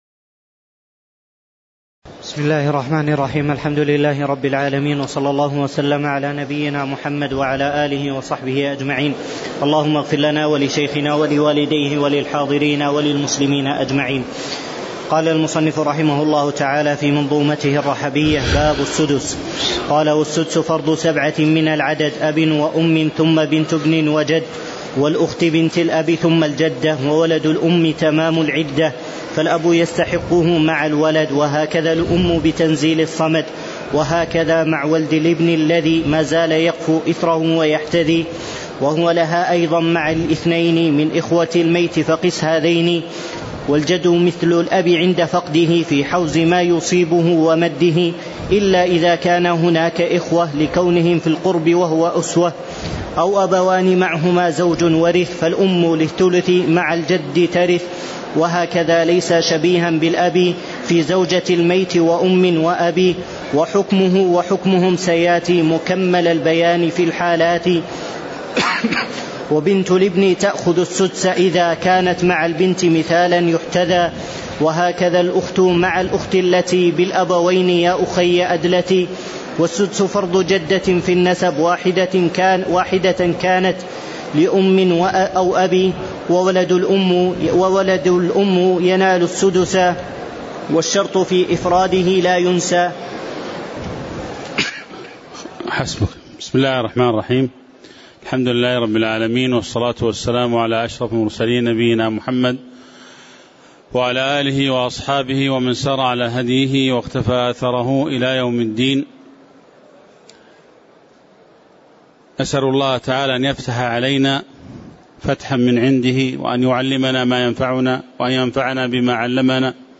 تاريخ النشر ١٤ صفر ١٤٣٨ هـ المكان: المسجد النبوي الشيخ